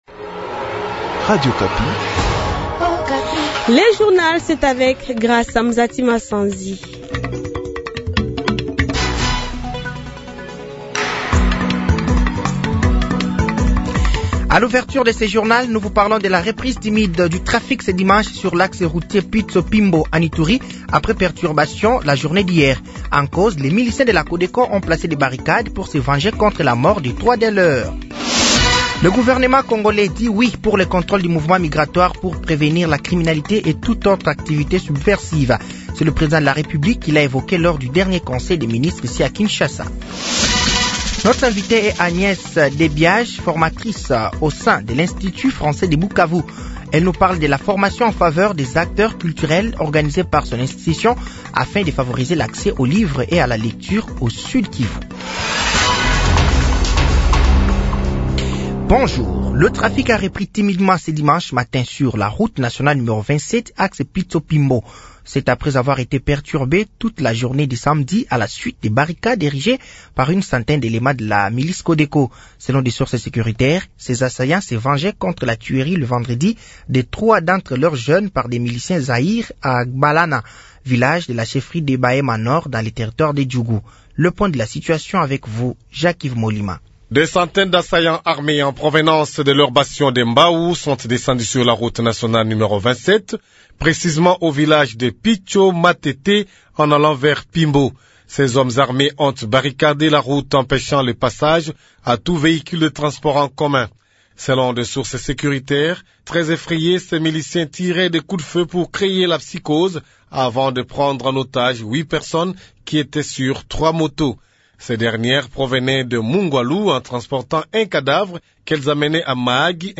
Journal français de 15h de ce dimanche 15 septembre 2024